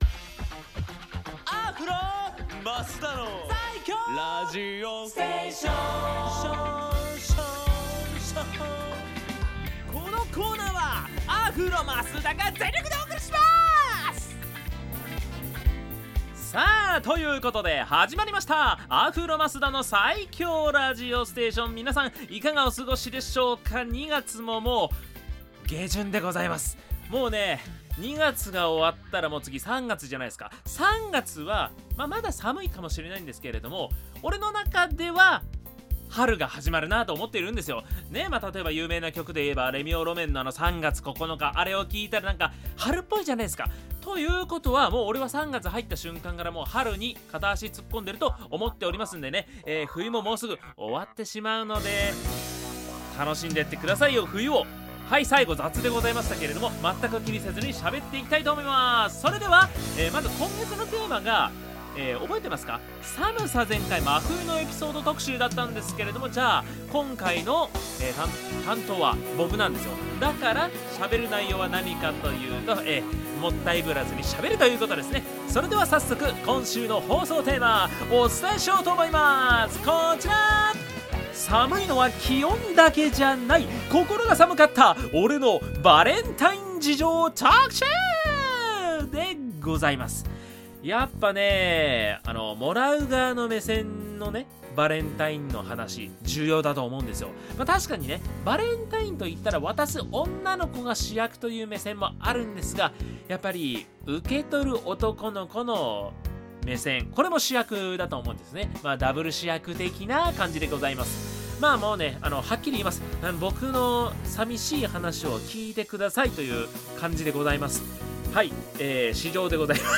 こちらのブログでは、FM83.1Mhzレディオ湘南にて放送されたラジオ番組「湘南MUSICTOWN Z」内の湘南ミュージックシーンを活性化させる新コーナー！
こちらが放送音源です♪